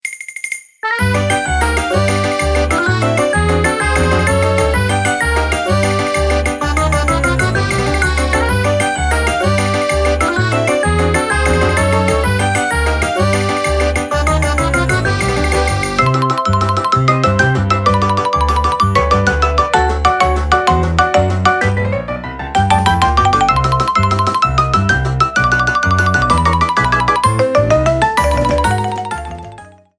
minigame music rearranged